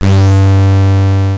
ihob/Assets/Extensions/RetroGamesSoundFX/Hum/Hum04.wav at master
Hum04.wav